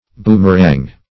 Boomerang \Boom"er*ang\, n.